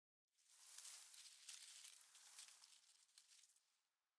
bushrustle3.ogg